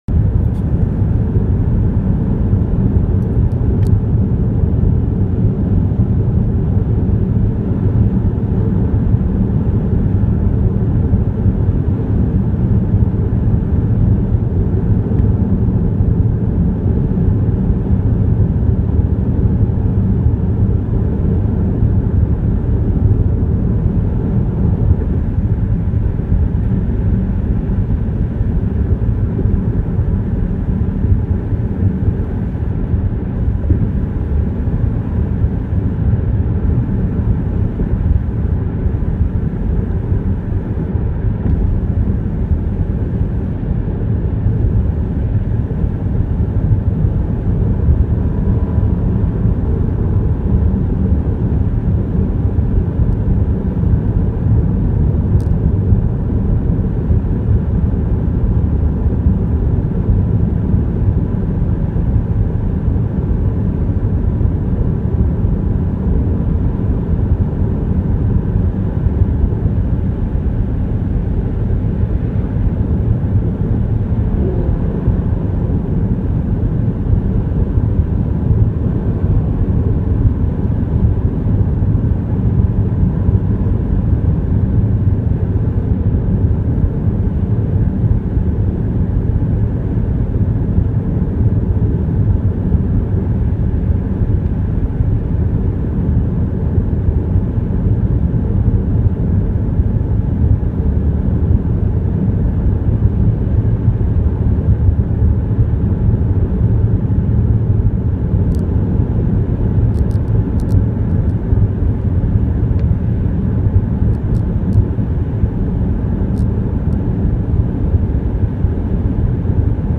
جلوه های صوتی
دانلود صدای رانندگی در ماشین 1 از ساعد نیوز با لینک مستقیم و کیفیت بالا